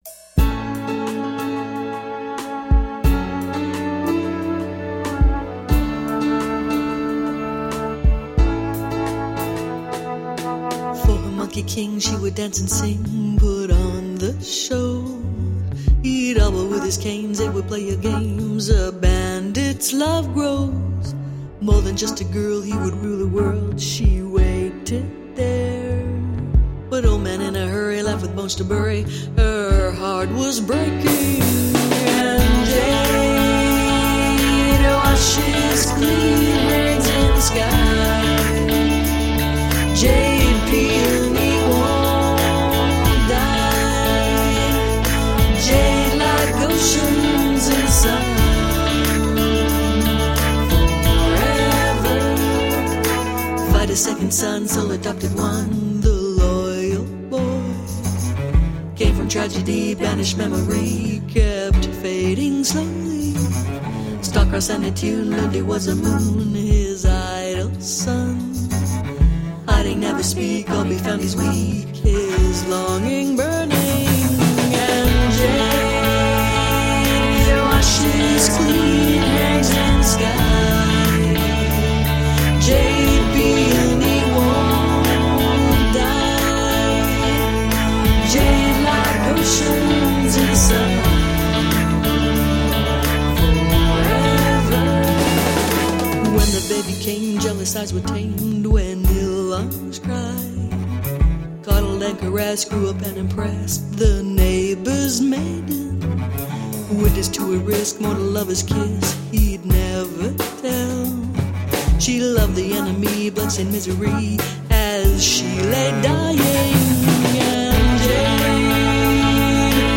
Jazzed up, funk-tinged eclectic pop..